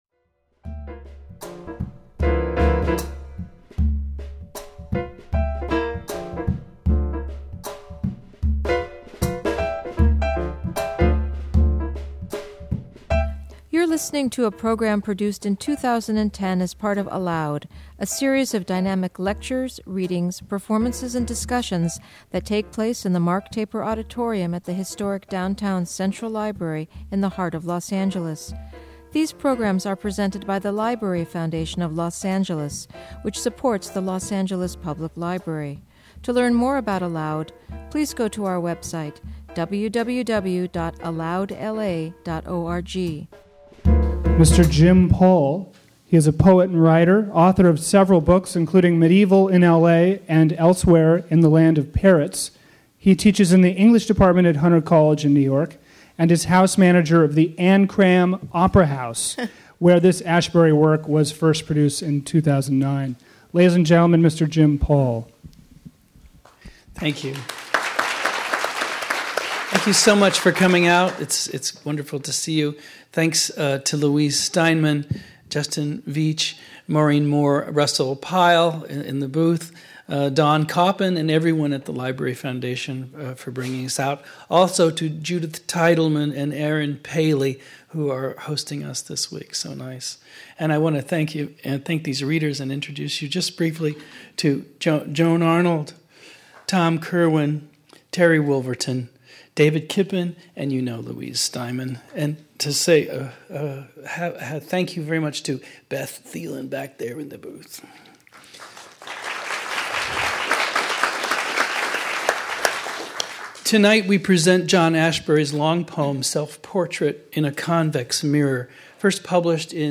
A staged reading of John Ashbery's great, dense work-one of the defining poems of the 20th century. Six readers, accompanied by projected text and image, illuminate and bring to life Ashbery's tonal shifts and juxtapositions.